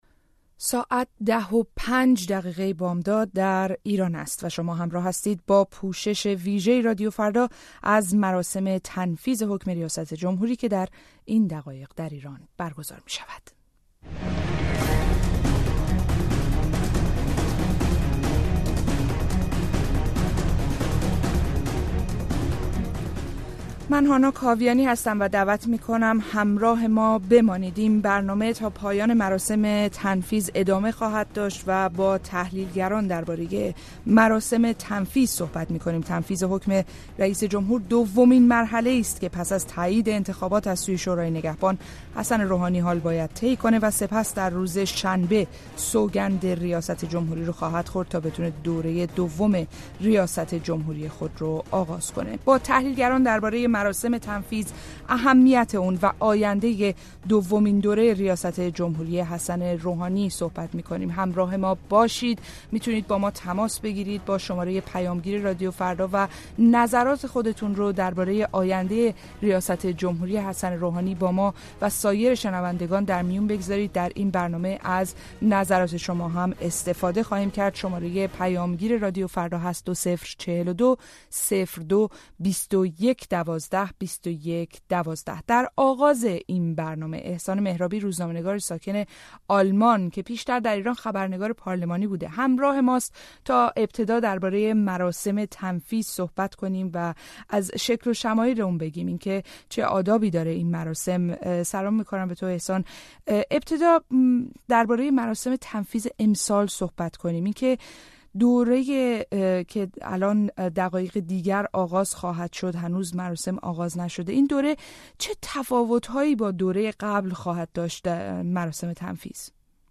گفتگوی رادیو فردا با تحلیلگران در مورد مراسم تنفیذ حکم ریاست جمهوری حسن روحانی